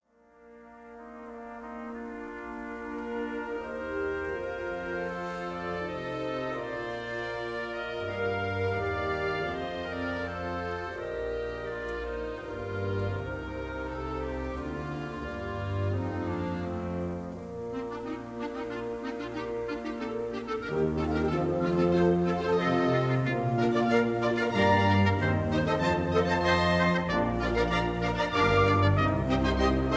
Recorded in mono in 1955.